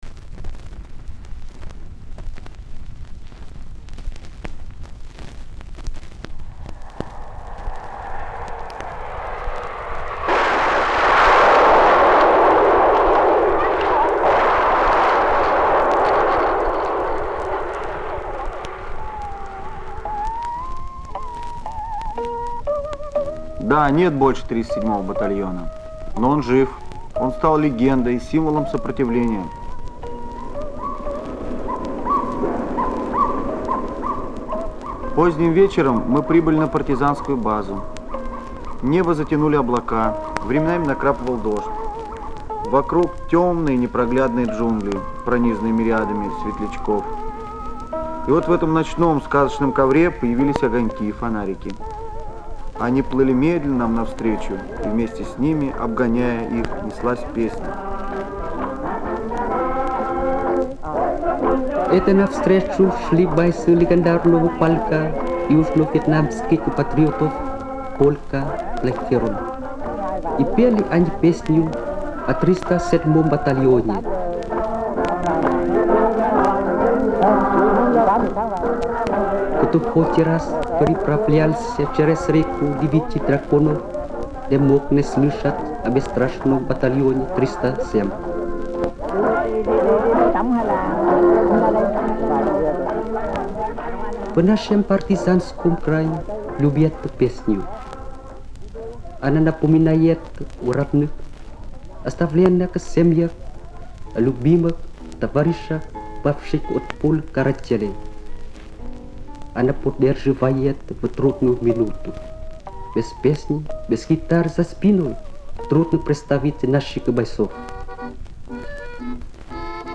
Репортаж из Южного Вьетнама.